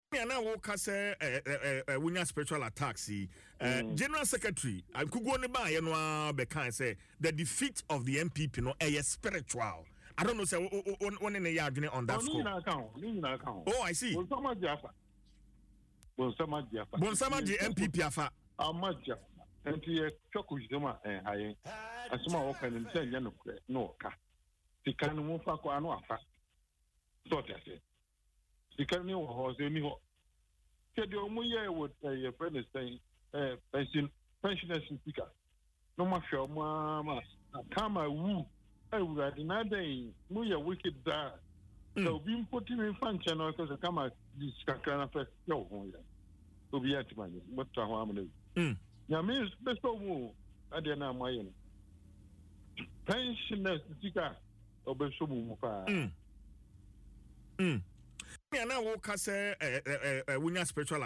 He made this statement during an interview on Adom FM’s Dwaso Nsem.